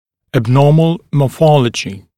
[æb’nɔːml mɔː’fɔləʤɪ][эб’но:мл мо:’фолэджи]атипичная морфология